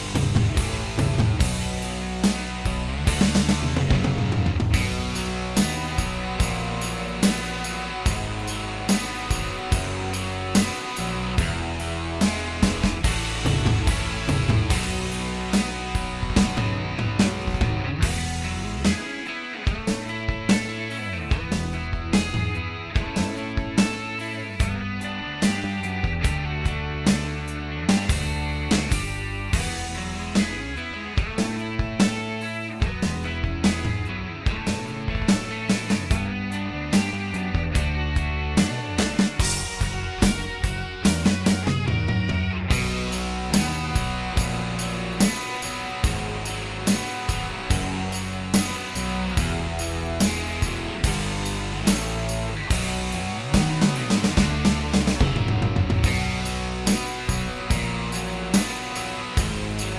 Minus Rhythm Guitar For Guitarists 5:21 Buy £1.50